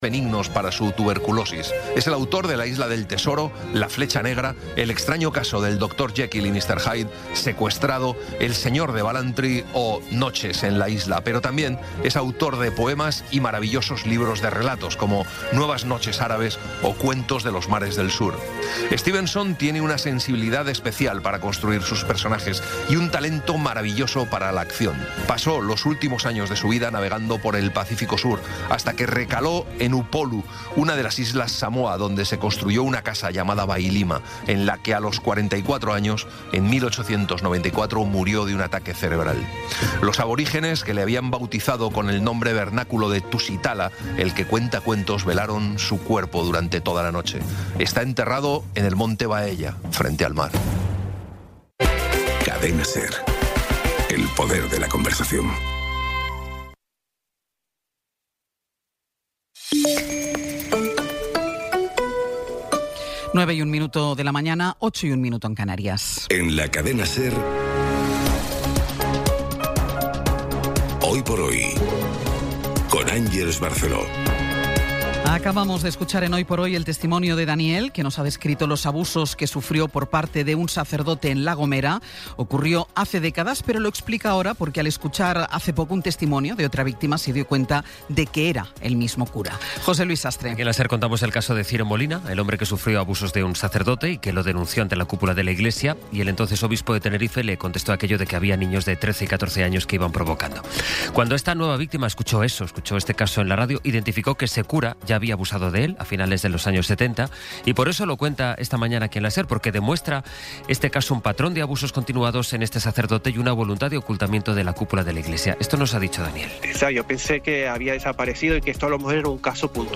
Resumen informativo con las noticias más destacadas del 21 de abril de 2026 a las nueve de la mañana.